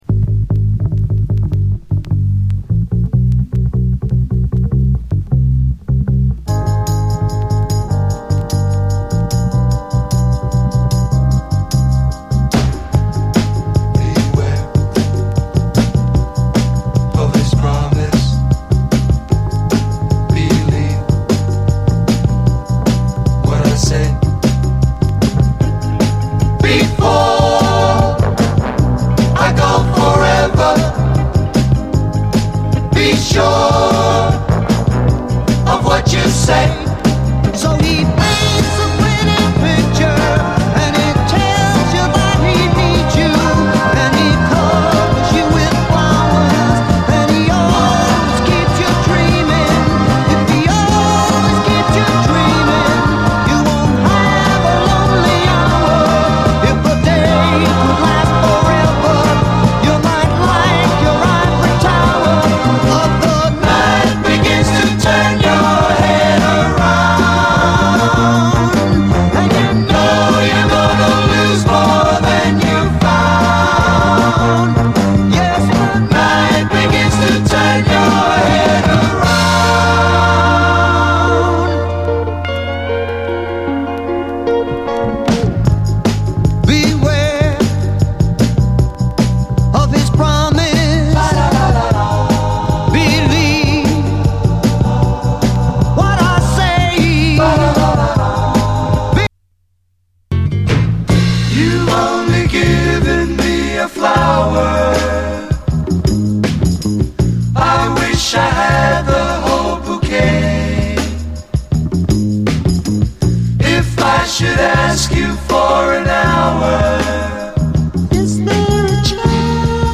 SOUL, 70's～ SOUL, 70's ROCK, ROCK
ポップ・ノーザン・ソウル
厳かなオルガンからジワジワと盛り上がる、独特な一曲！